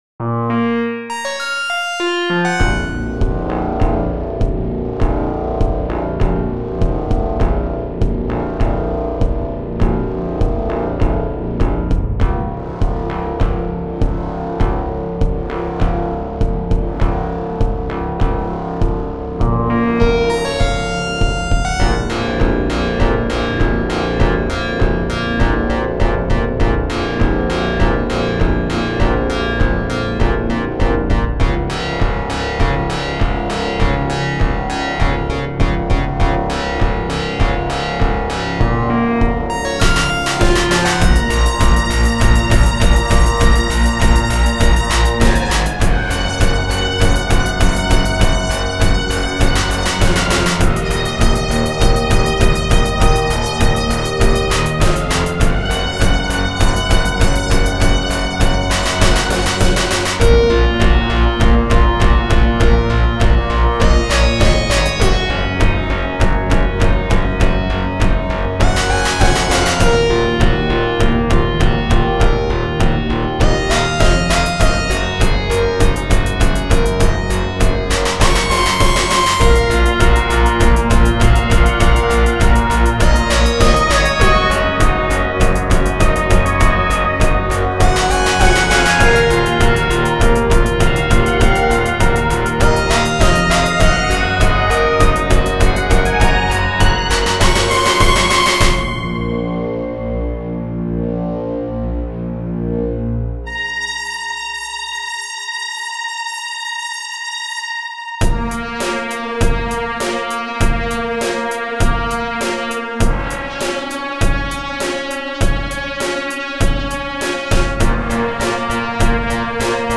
These tracks have been sequenced on Linux with Rosegarden.
All the drums are performed by Hydrogen.